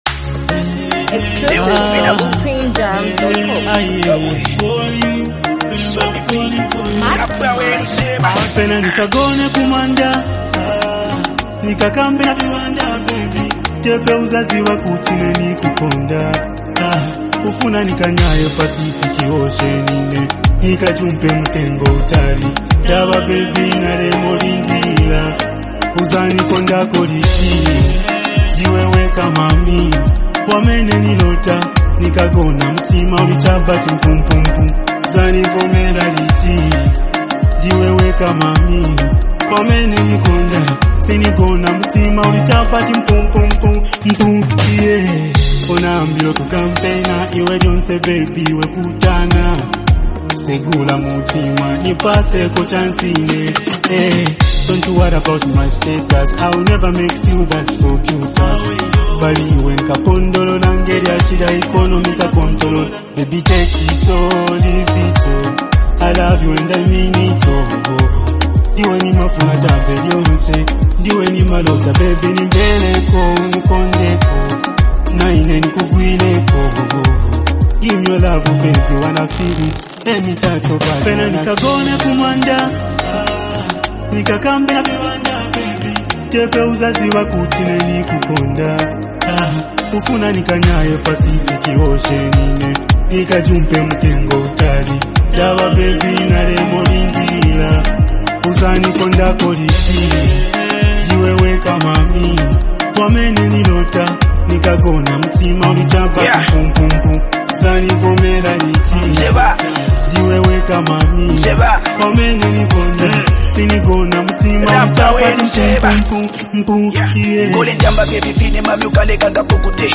an emotional love song
Through expressive lyrics and a soothing vibe